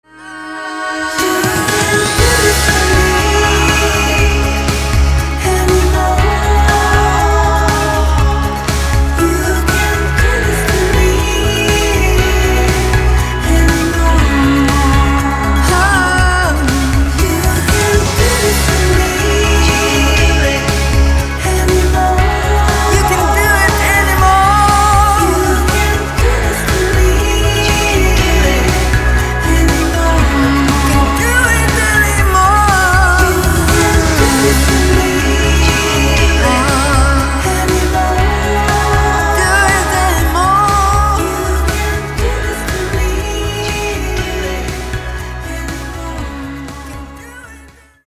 • Качество: 320, Stereo
женский вокал
dance
поп-рок
рок